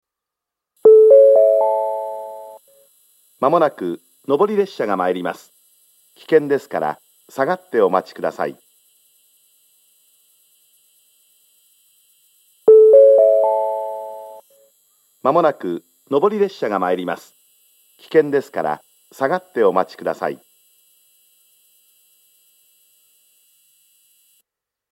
okunai-1bannsenn-sekkinn.mp3